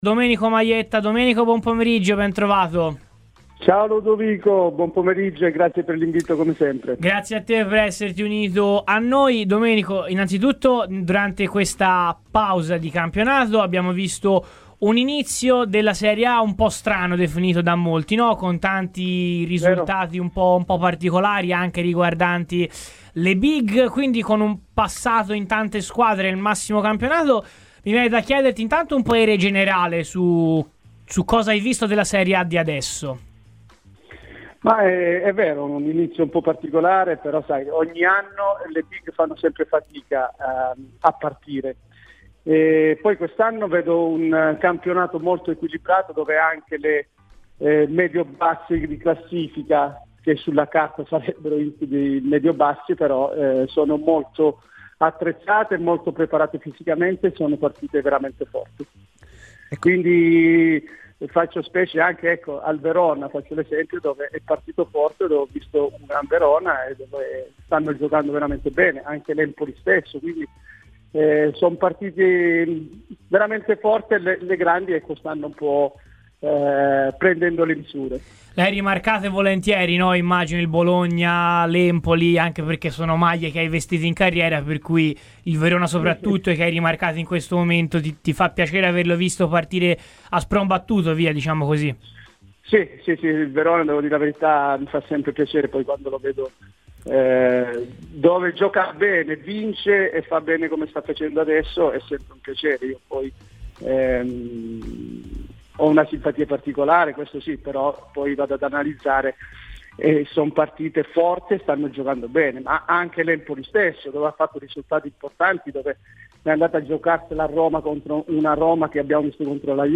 è intervenuto questo pomeriggio a Radio FirenzeViola nel corso della trasmissione “Viola Weekend”.